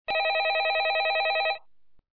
call-in.m4a